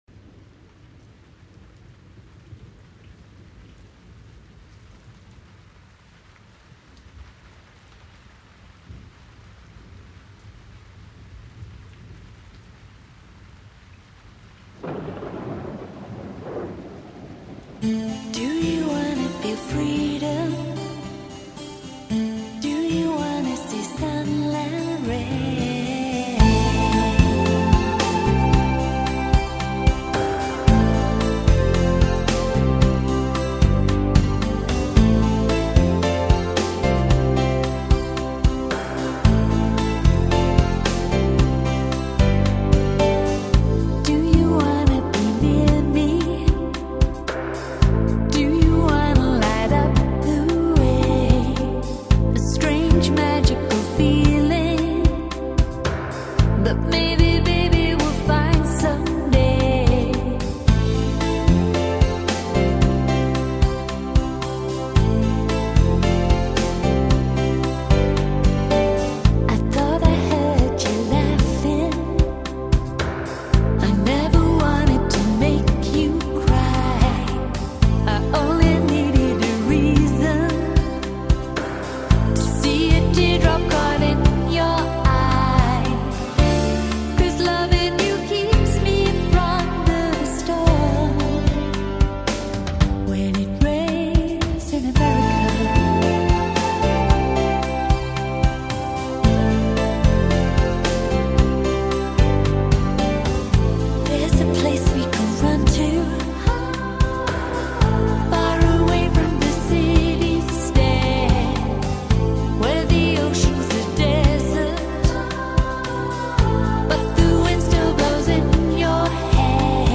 音乐类型：古典音乐